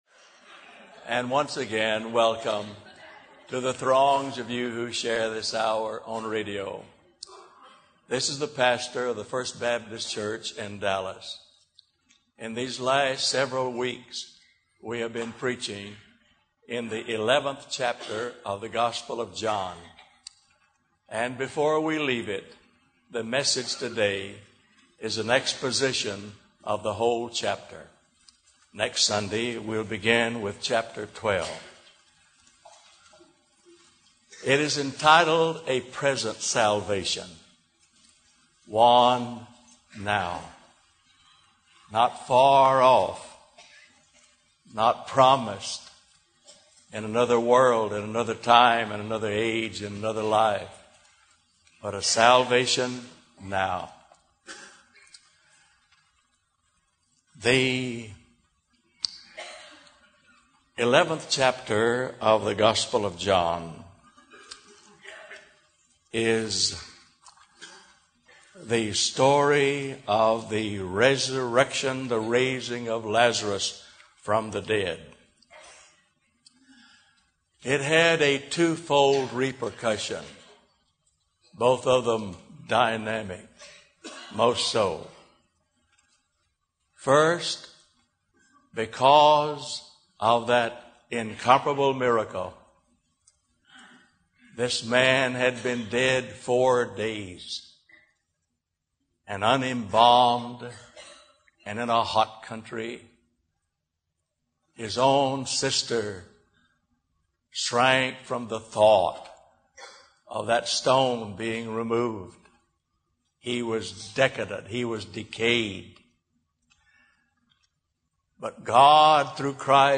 A Present Salvation – W. A. Criswell Sermon Library